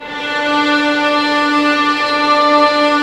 Index of /90_sSampleCDs/Roland LCDP08 Symphony Orchestra/STR_Vls Sul Pont/STR_Vls Pont wh%